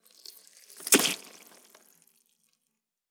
Blood_1.wav